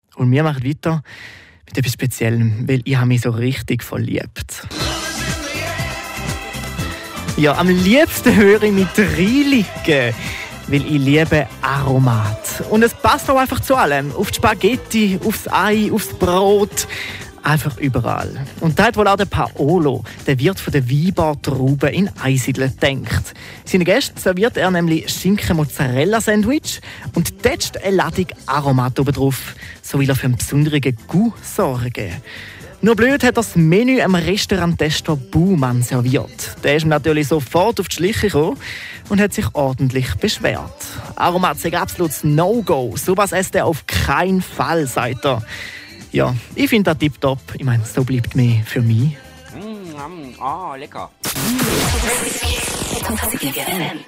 Hier kam mir die Spezial-Betten-Idee eine Minute bevor ich LIVE war.